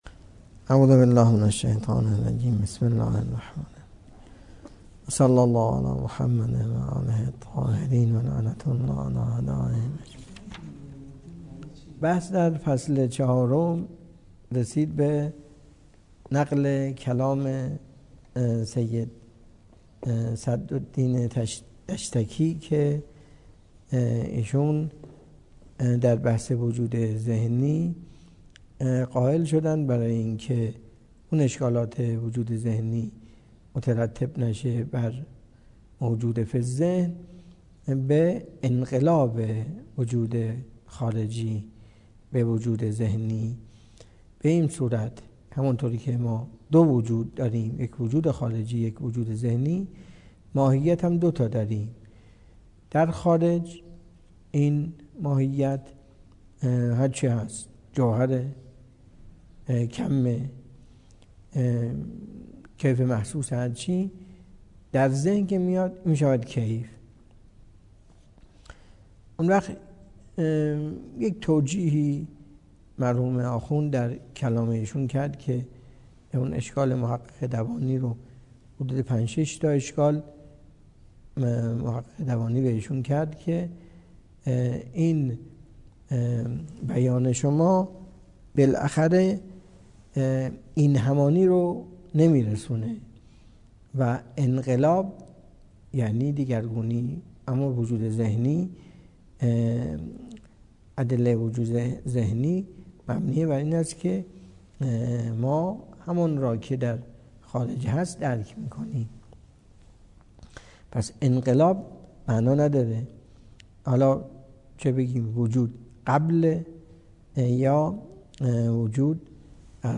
درس فلسفه اسفار اربعه